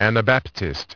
Транскрипция и произношение слова "anabaptist" в британском и американском вариантах.